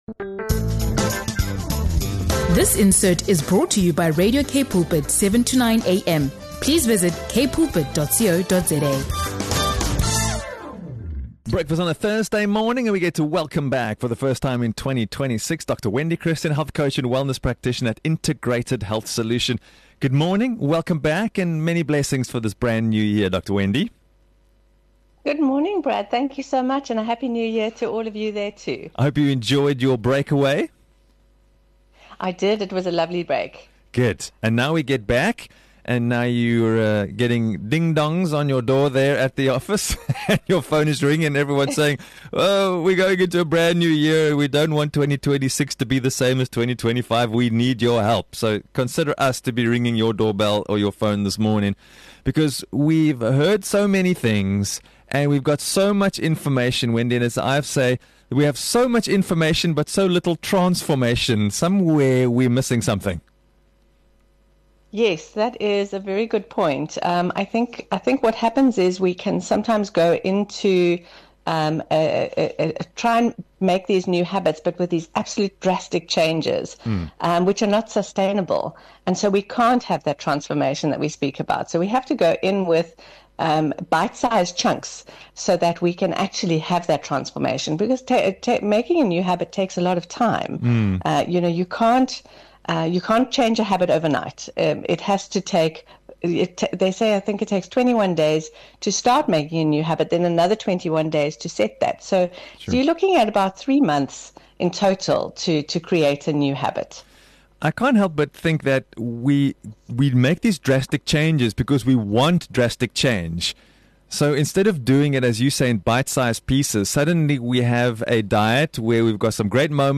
In this insightful conversation